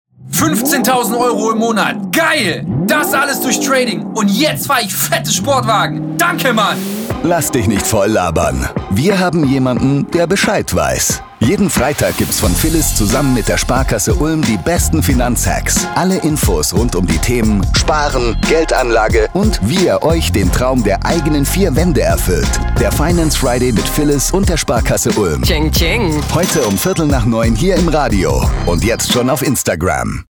Trailer zum Finance Friday